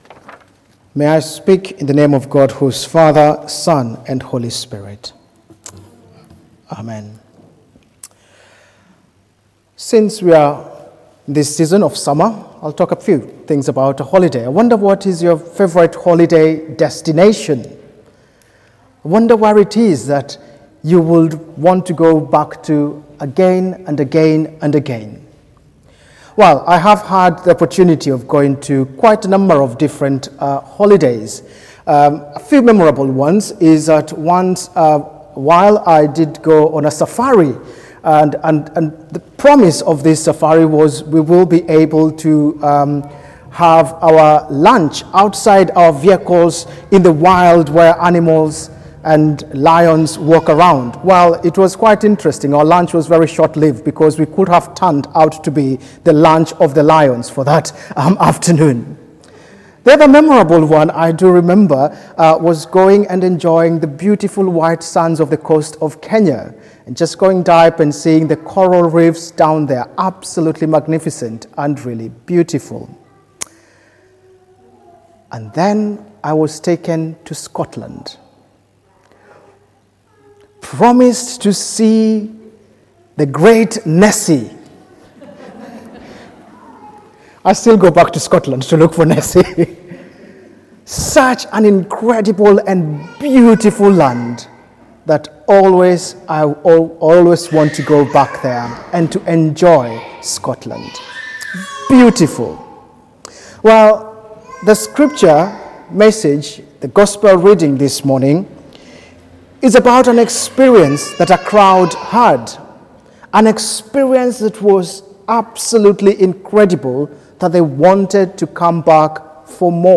Sermon: Feeding the 5000 (2) | St Paul + St Stephen Gloucester